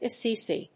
音節As・si・si 発音記号・読み方
/əsíːsi(米国英語), ʌˈsi:si:(英国英語)/